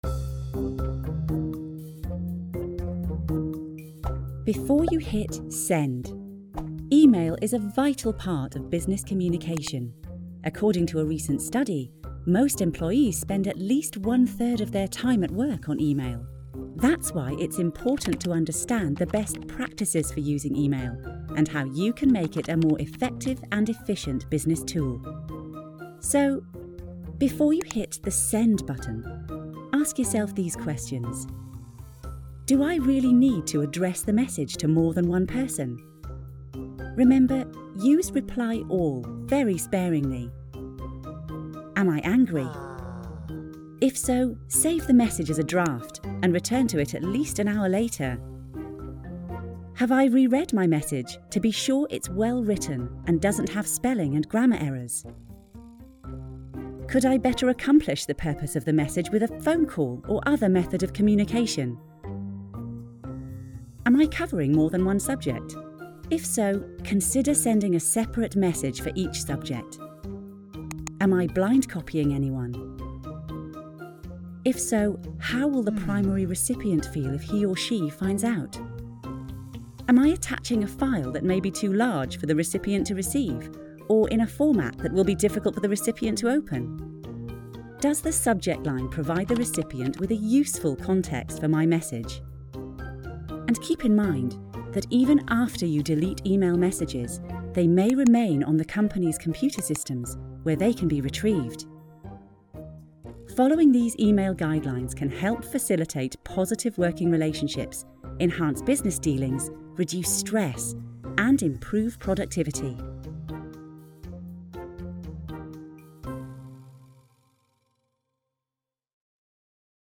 Female
English (British)
Adult (30-50), Older Sound (50+)
Friendly, Professional
Friendly Staff Explainer